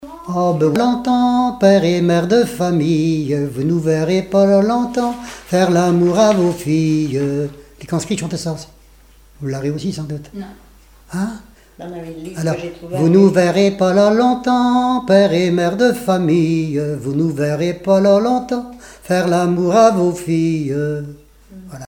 Chants brefs - Conscription
gestuel : à marcher
Témoignages et chansons
Pièce musicale inédite